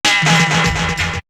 Break 12.wav